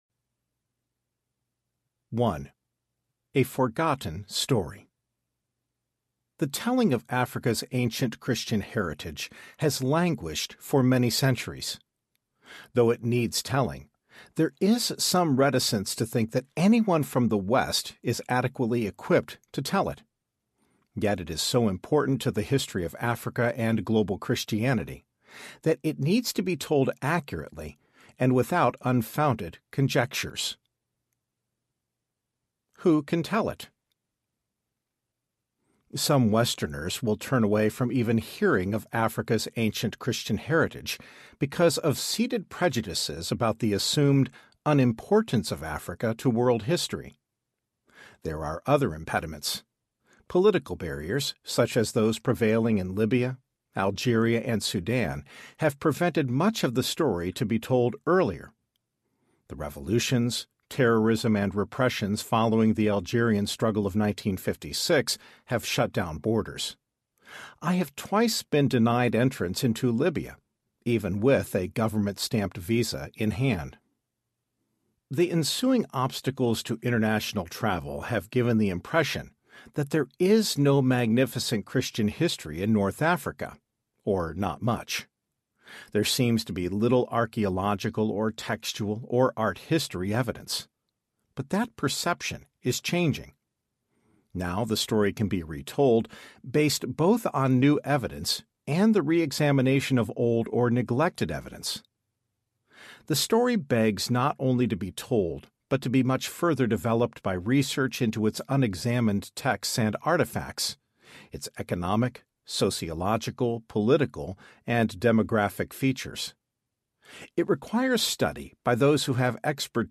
How Africa Shaped the Christian Mind Audiobook
7.1 Hrs. – Unabridged